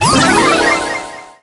sniper_ulti_01.ogg